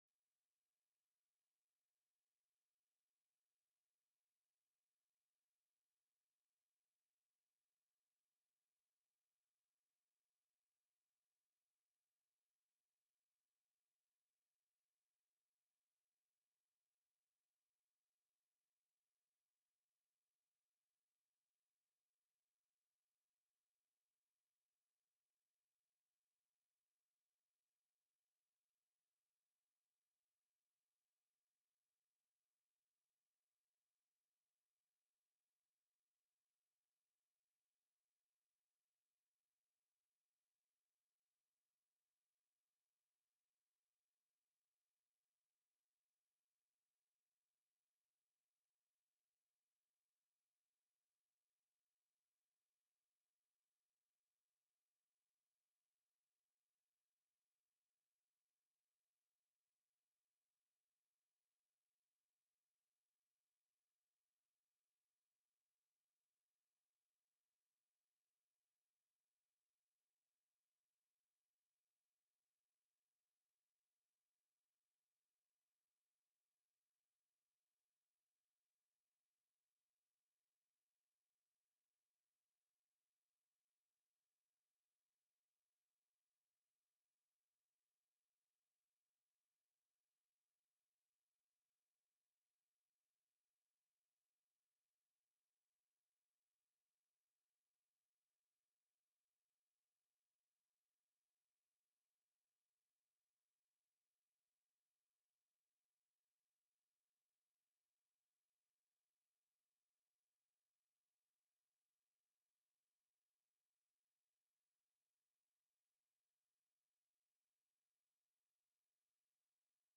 The audio recordings are captured by our records offices as the official record of the meeting and will have more accurate timestamps.
Co-Chair Foster called the House Finance Committee meeting to order at 1:33 p.m.